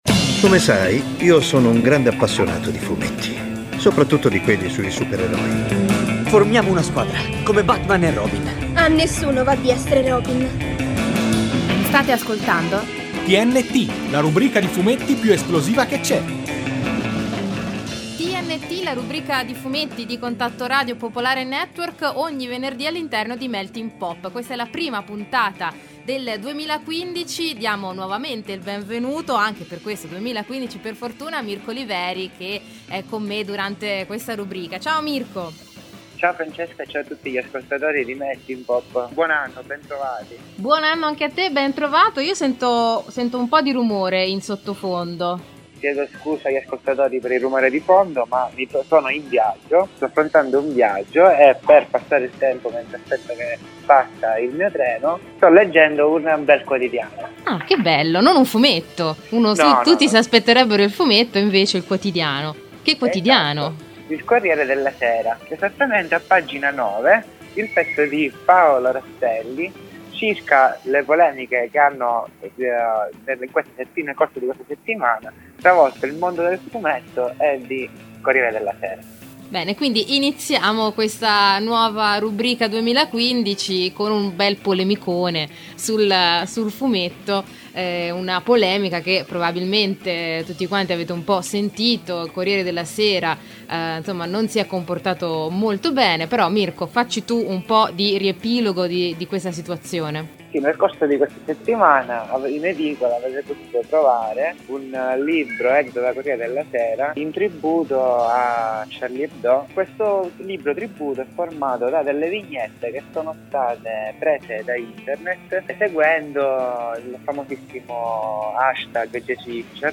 Nuovo appuntamento con il podcast di TNT, la trasmissione radiofonica in onda tutti i venerdì su Contatto Radio.
TNT-10_POLEMICA-Corriere-della-Sera-no-musica.mp3